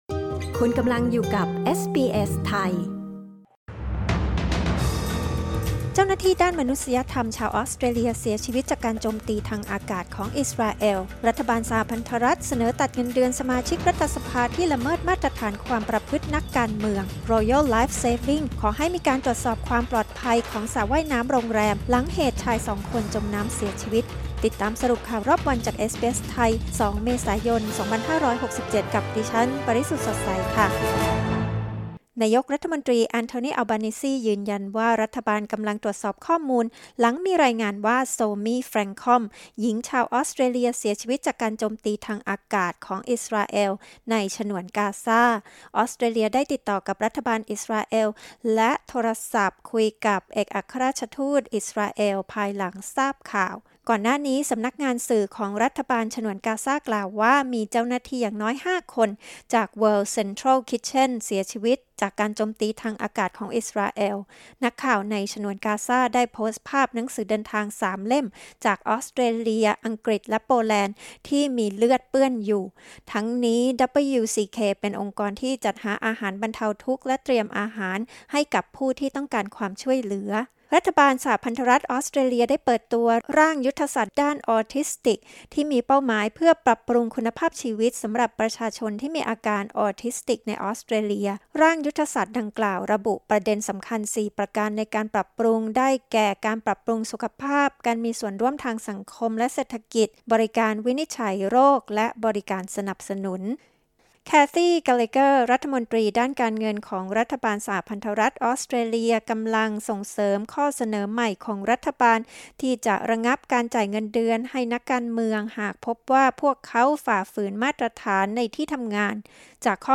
สรุปข่าวรอบวัน 2 เมษายน 2567